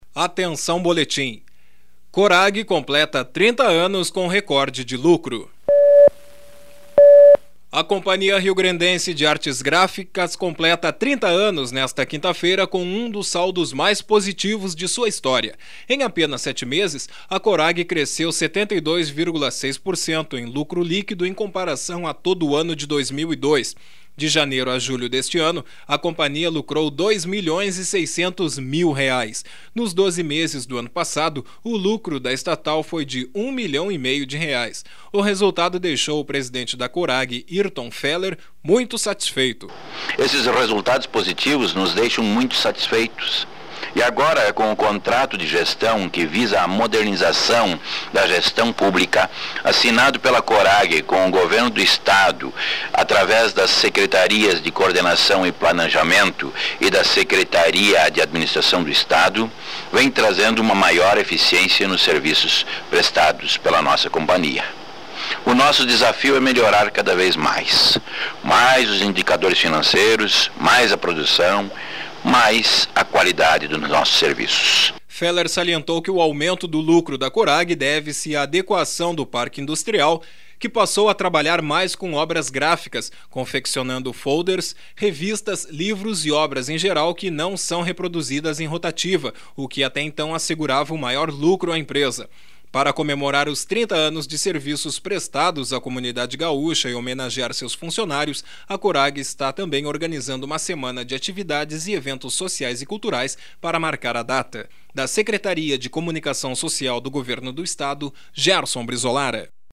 A Companhia Riograndense de Artes Gráficas completa 30 anos nesta quinta-feira, com um dos saldos mais positivos de sua história. (Sonora